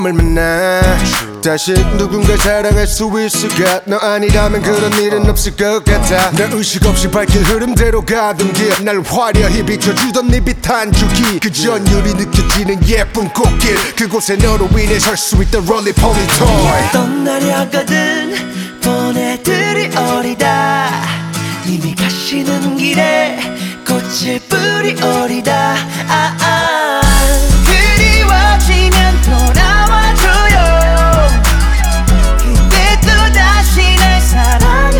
Жанр: Поп / R&b / K-pop / Соул